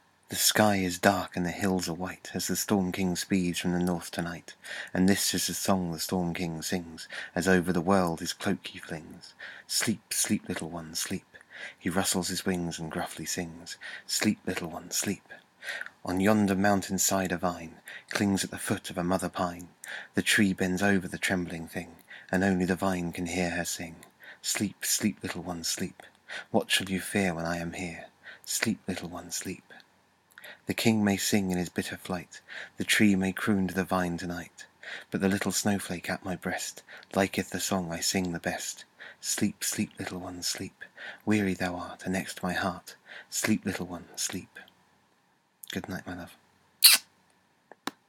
norse_lullaby.ogg